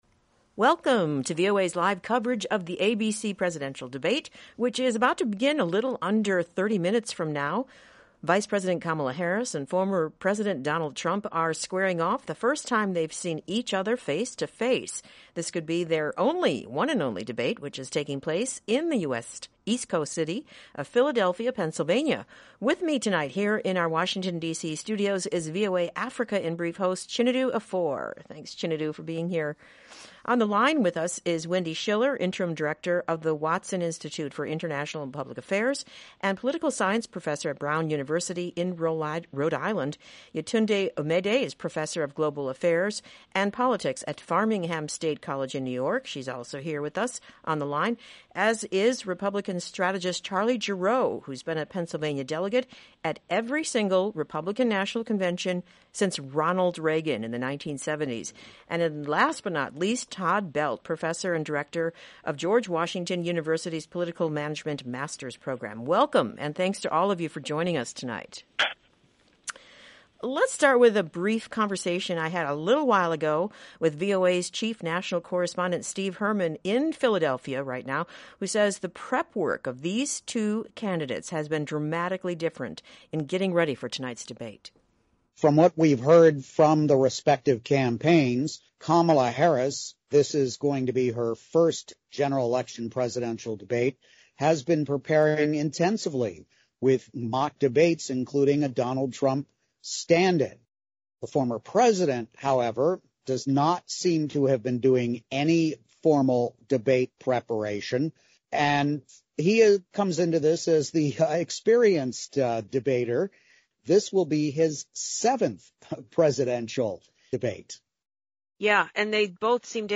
U.S. presidential candidates Kamala Harris and Donald Trump clash over difference in policies during the 2024 presidential debate that will witness both leaders attempting to attract voters. In our special VOA programming, analysts speak on the debate and how it impacts the outcome of the 2024 race to the White House.